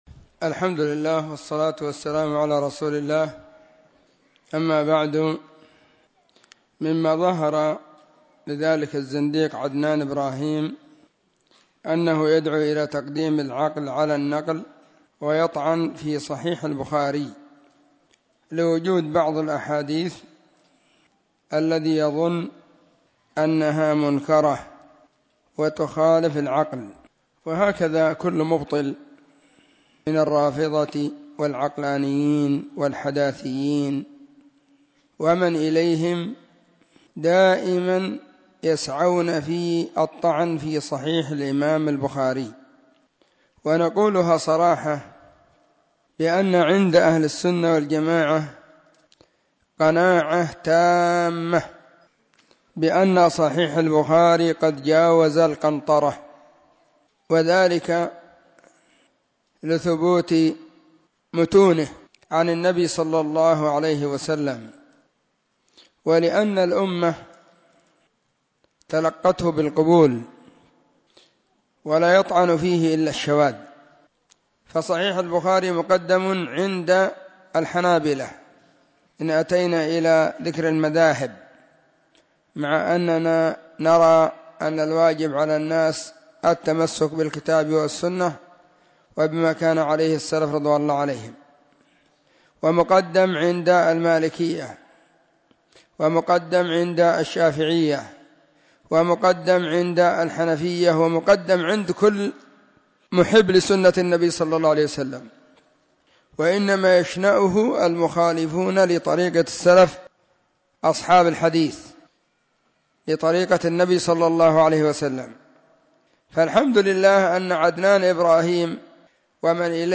📢 مسجد الصحابة – بالغيضة – المهرة – اليمن حرسها الله.
الأربعاء 21 ربيع الأول 1443 هــــ | الردود الصوتية | شارك بتعليقك